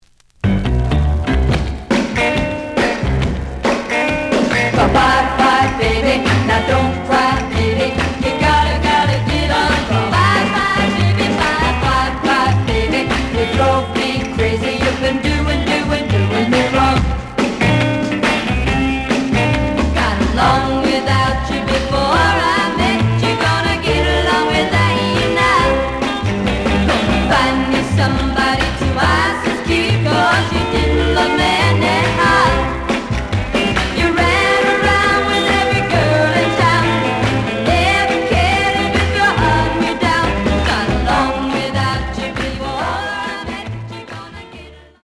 The audio sample is recorded from the actual item.
●Genre: Rock / Pop
Slight sound cracking on A side.